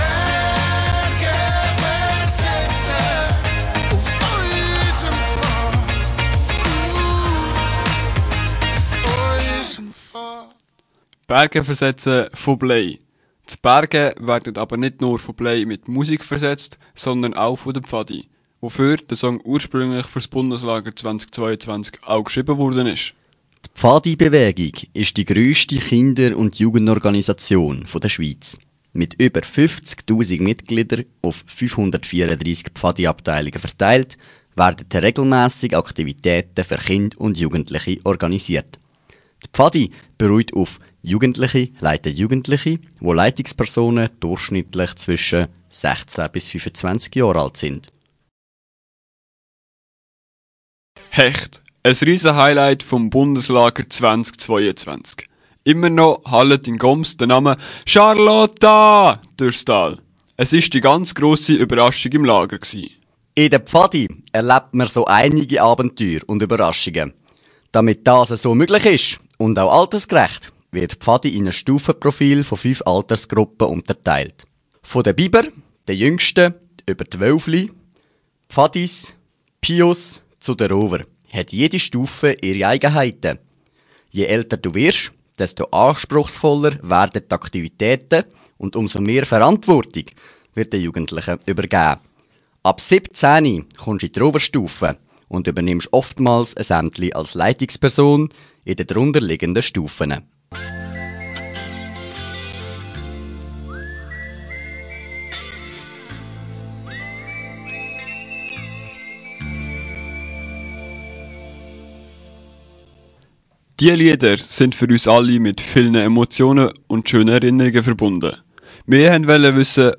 Wir konnten während des Beitrags ein Interview auf der Strasse führen und näher darauf eingehen, wie die Pfadi aufgebaut ist.